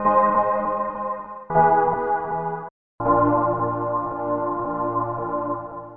描述：Cd切割，从CD上切下，并用Sawcutter 1,2或其它切割，用FX处理，标准化。